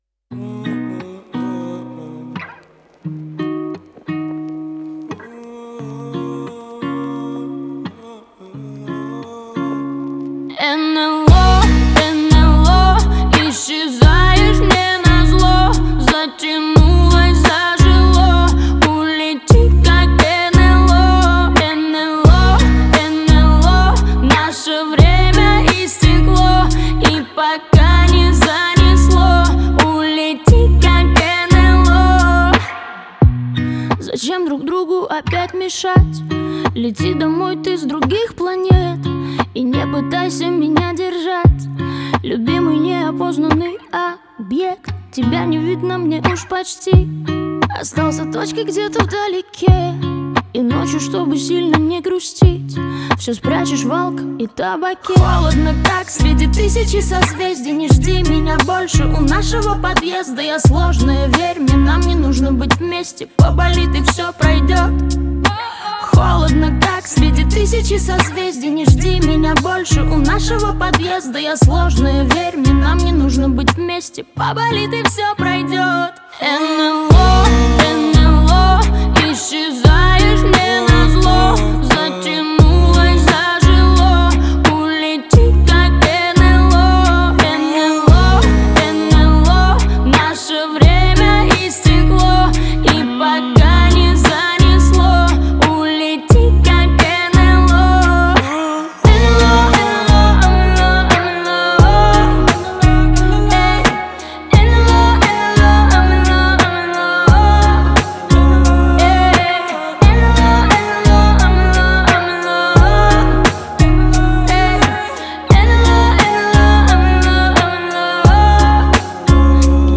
создающее эффект диалога и эмоционального взаимодействия.